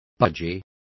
Complete with pronunciation of the translation of budgies.